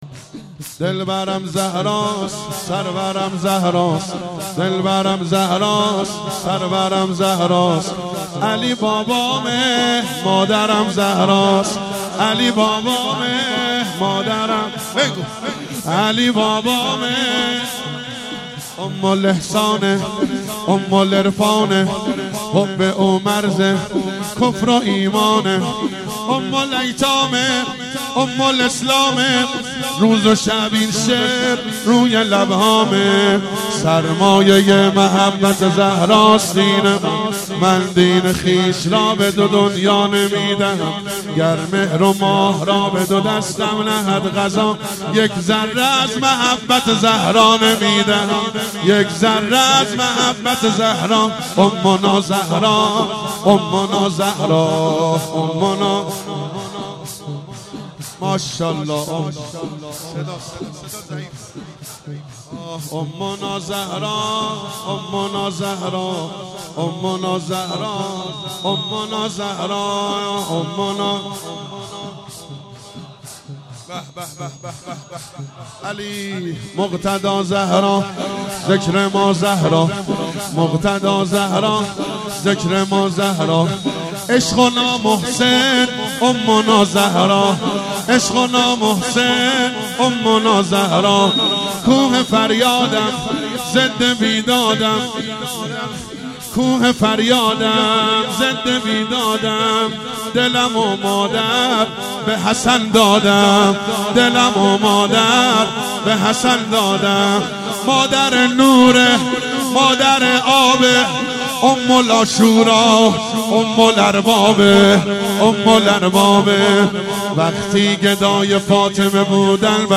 مراسم شب سوم فاطمیه ۱۳۹۶
مداحی